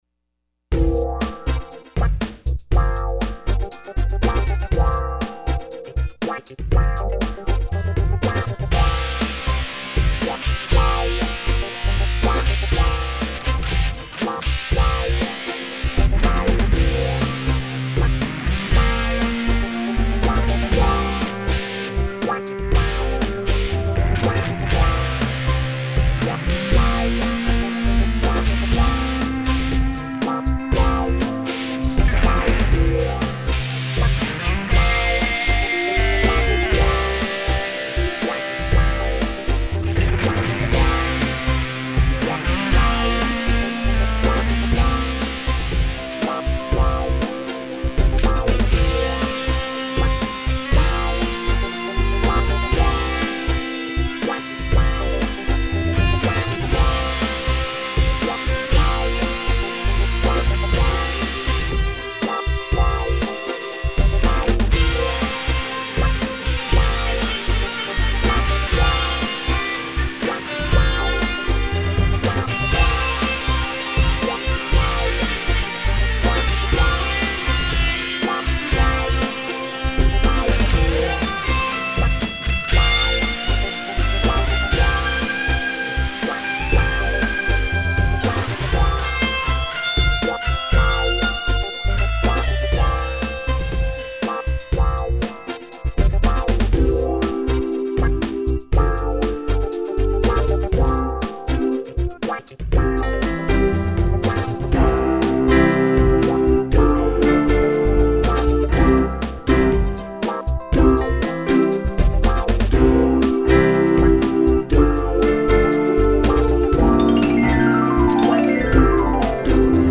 The Secret Hidden Gritty Underground Raw Audio Files